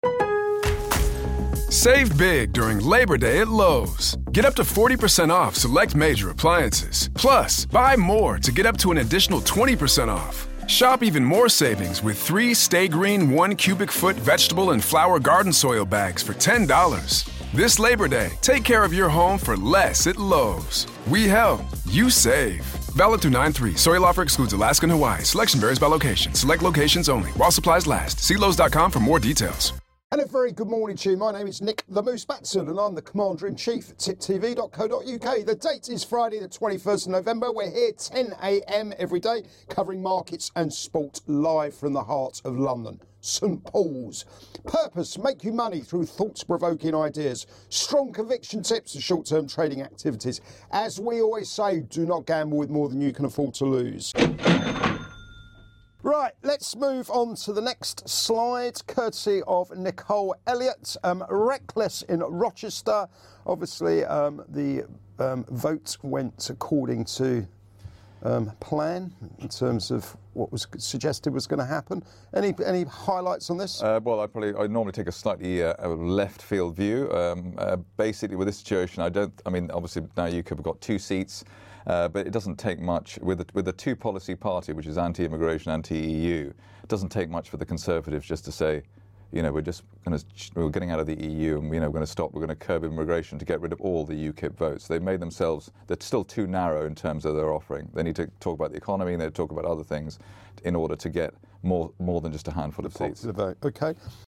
Live Market Round Up & Soapbox Thoughts